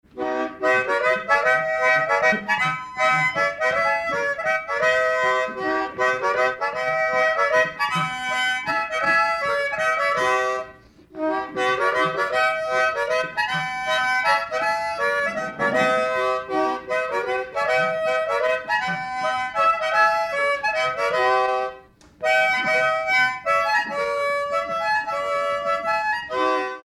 Mazurka
danse : mazurka
circonstance : bal, dancerie
Pièce musicale inédite